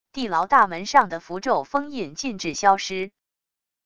地牢大门上的符咒封印禁制消失wav音频